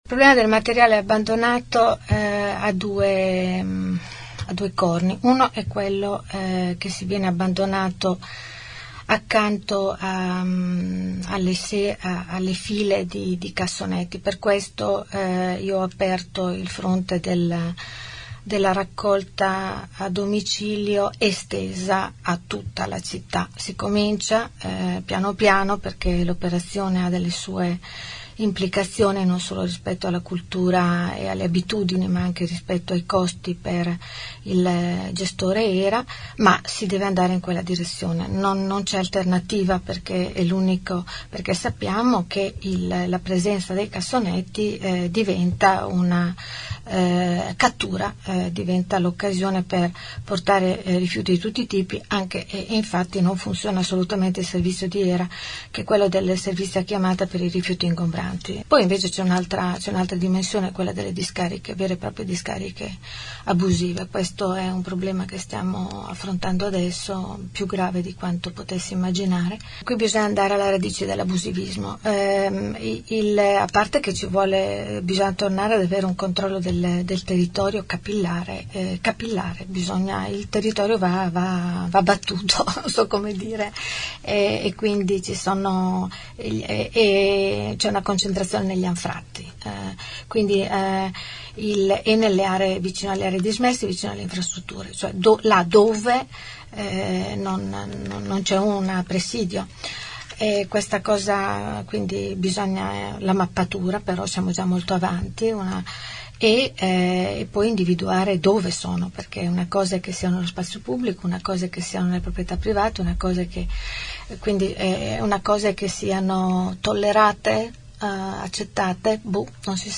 Nei nostri studi l’assessore Gabellini ha detto che la raccolta a domicilio dovrà arrivare in tutta la città, perché i cassonetti favoriscono l’abbandono dei rifiuti.